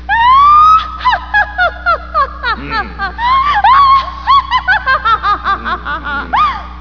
Naga laugh.